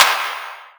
Clap 06.wav